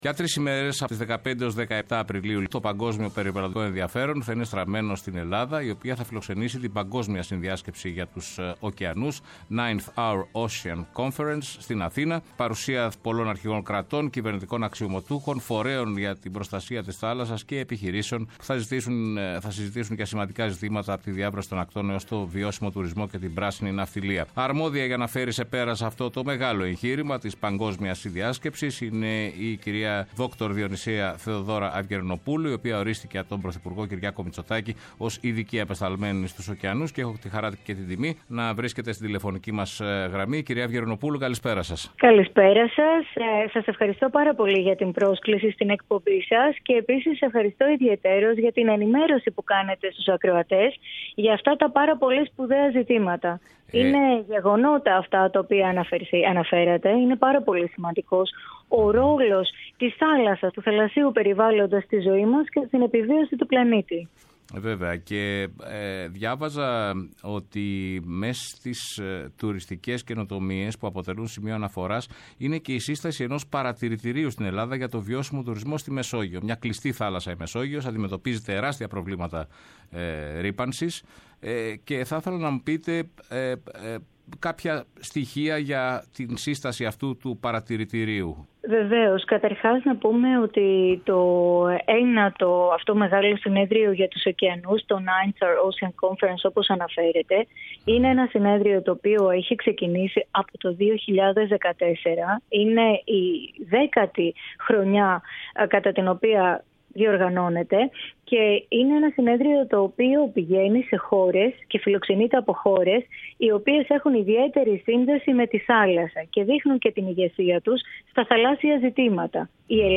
μιλά η Δρ. Διονυσία-Θεοδώρα Αυγερινοπούλου, βουλευτής ΝΔ και Ειδική Απεσταλμένη της χώρας μας για τους Ωκεανούς